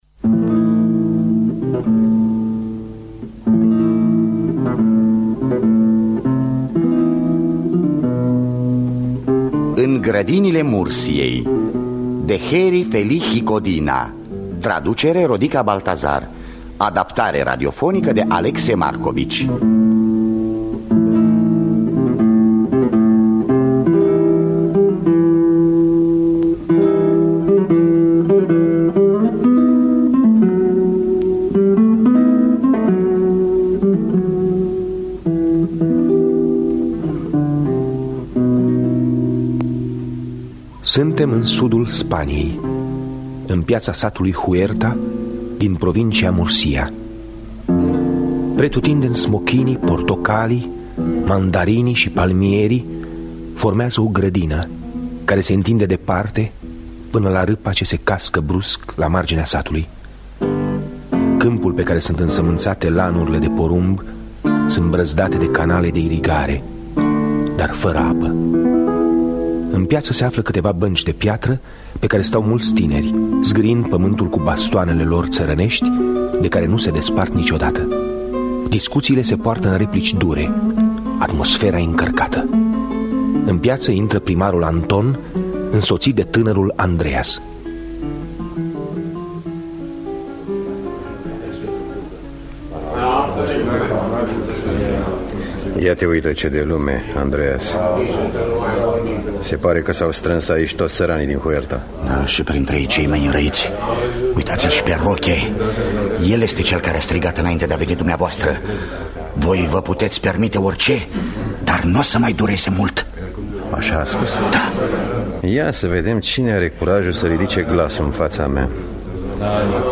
Adaptarea radiofonică de Alexe Marcovici.